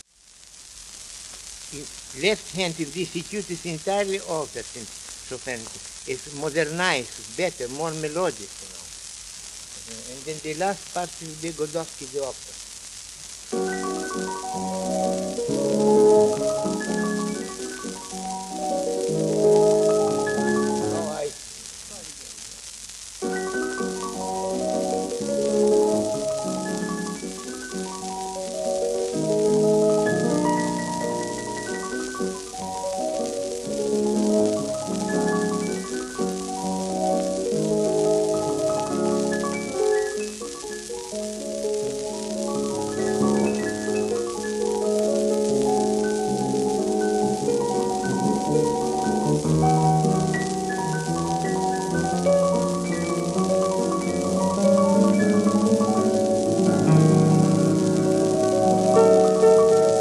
晩年の録音した数枚で”それ”が再現されていますが、これもそんな一枚です。